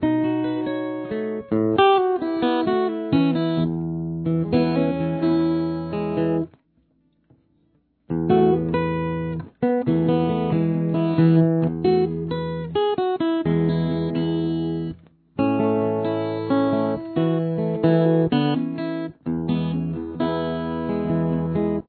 The guitar will be in standard tuning.